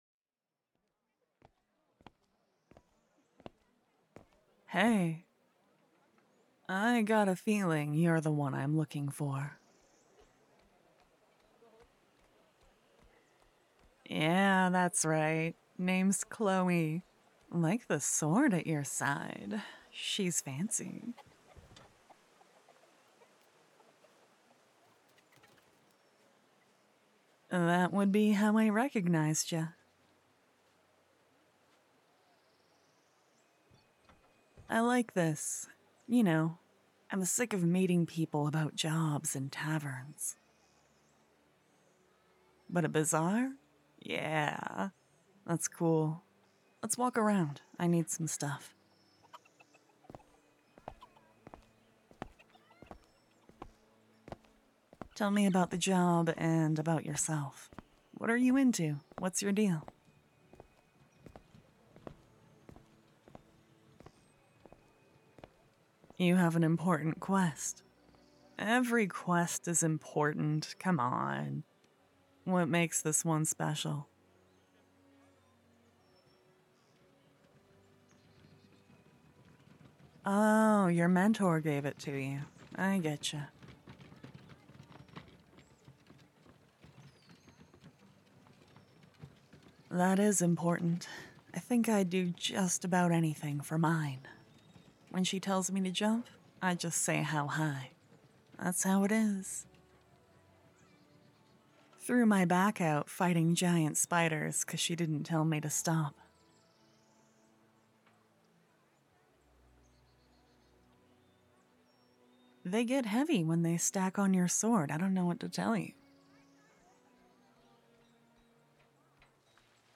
Different settings, different kinds of characters, voices and sound effects.
I don't do these types of characters often...~ I'm excited for you to hear this new series and I hope you like it~ There is a version of this audio with louder background music (more suited to be played on speakers than headphones) attached as 'Crystal Garden Chole PATREON LOUDER BGM.mp3' Edit: Forgot to say there are no warnings for this audio!